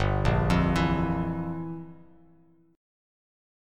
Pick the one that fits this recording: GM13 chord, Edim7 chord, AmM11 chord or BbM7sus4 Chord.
GM13 chord